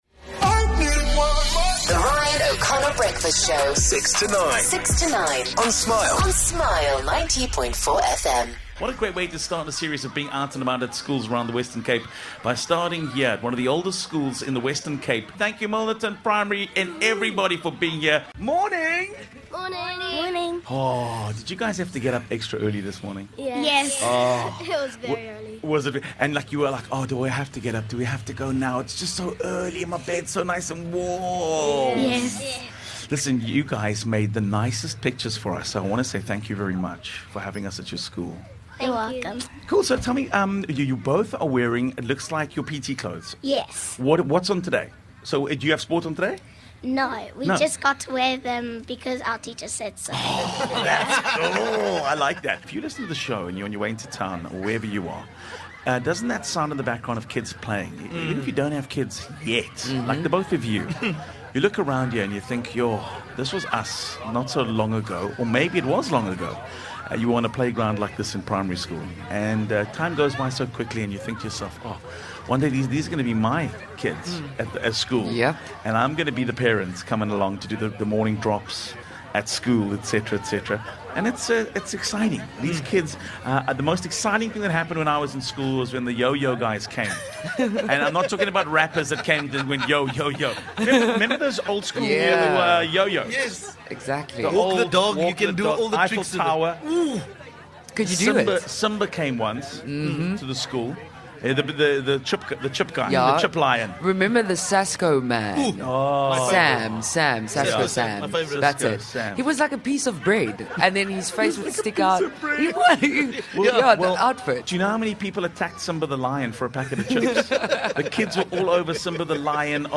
23 Feb The show live from Milnerton Primary